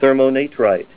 Help on Name Pronunciation: Name Pronunciation: Thermonatrite + Pronunciation
Say THERMONATRITE Help on Synonym: Synonym: ICSD 6293   PDF 8-448